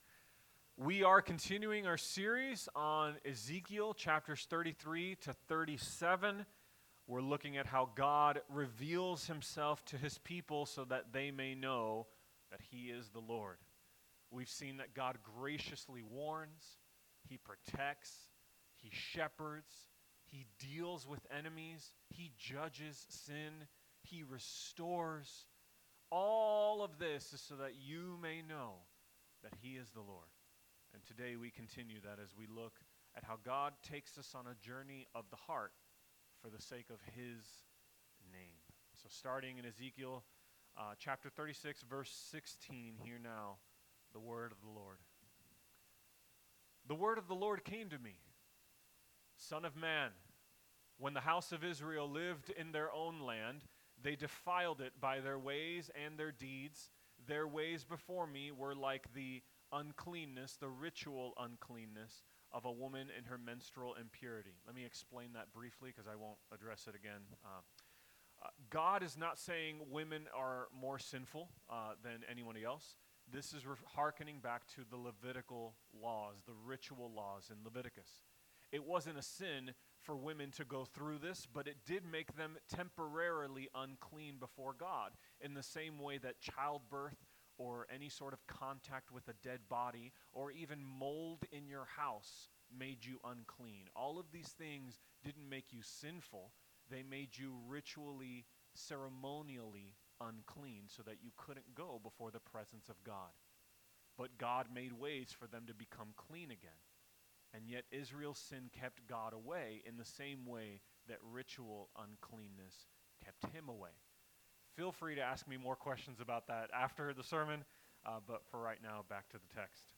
Sermons
Sermons from Treasure Coast Presbyterian Church: Stuart, FL